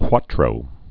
(kwätrō)